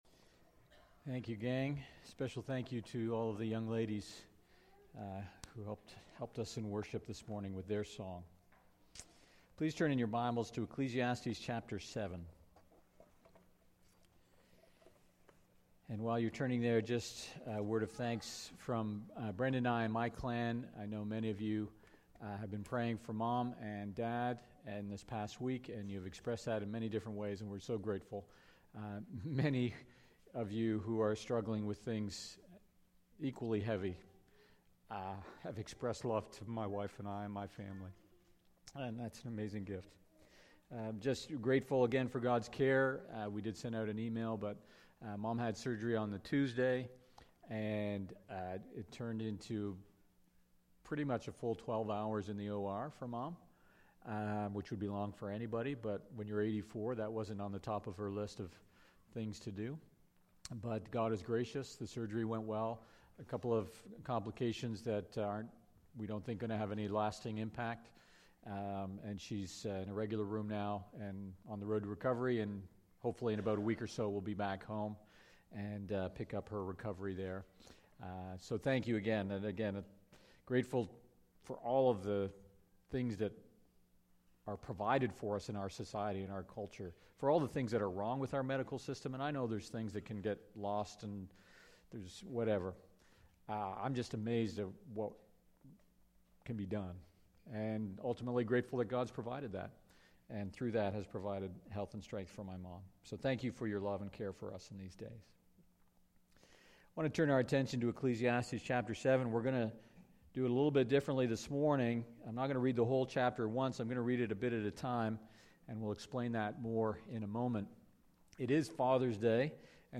Sermons | Port Perry Baptist